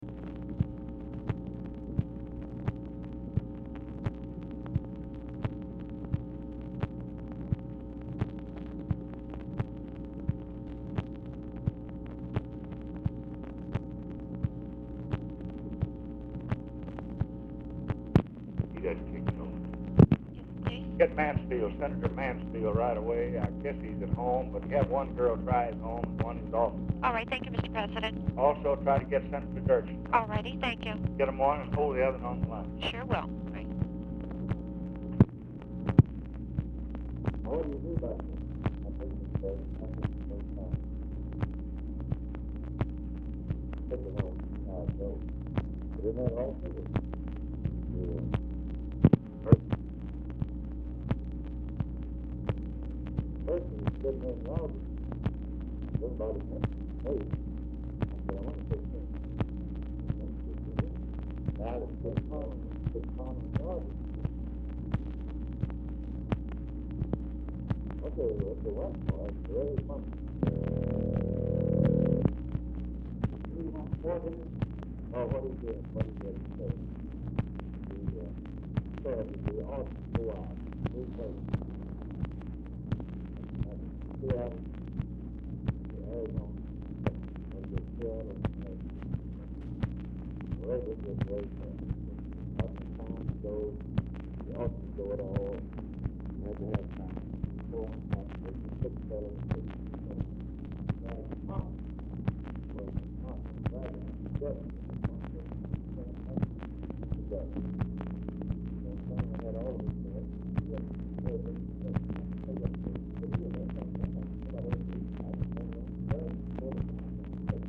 Format Dictation belt
Location Of Speaker 1 Oval Office or unknown location
Speaker 2 TELEPHONE OPERATOR Specific Item Type Telephone conversation